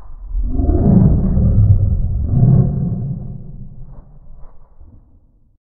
fami_grumble.ogg